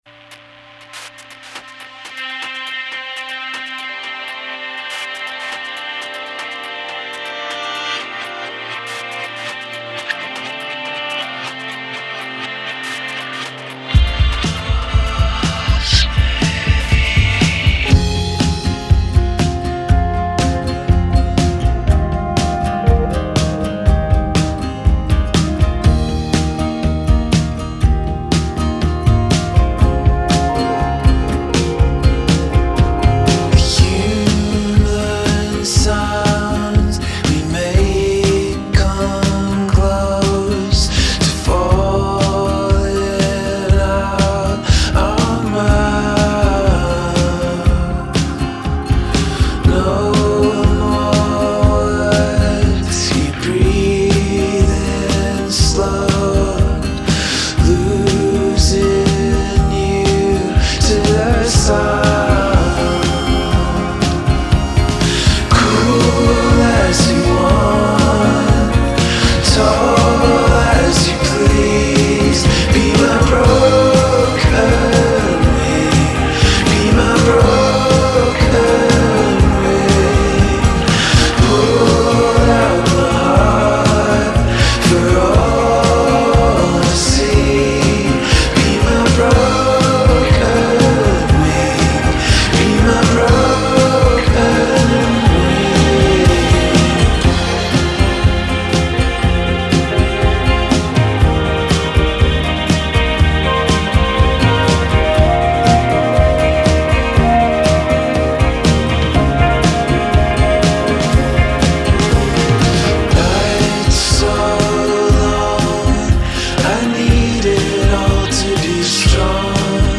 Gorgeous 21st-century folk rock